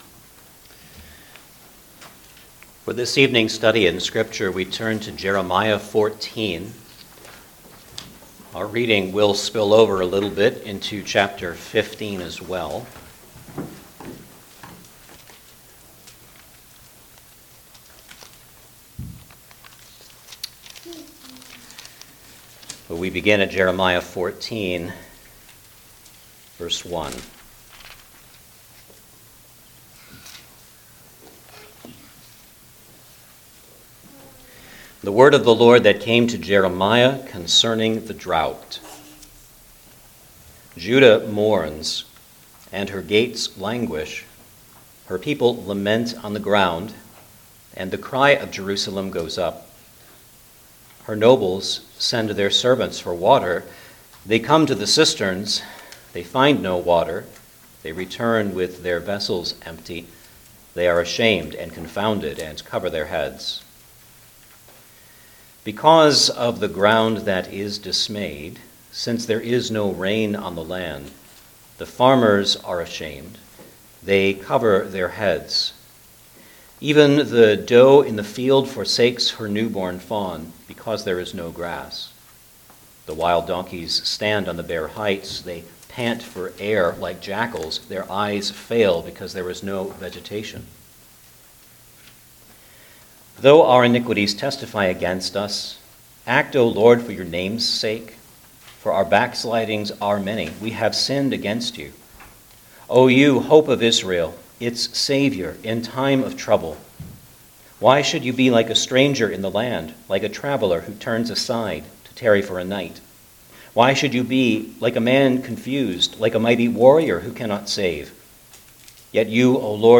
Jeremiah Passage: Jeremiah 14:1 – 15:9 Service Type: Sunday Evening Service Download the order of worship here .